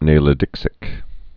(nālĭ-dĭksĭk)